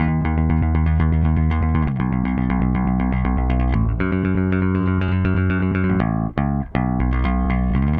Power Pop Punk Bass 02b.wav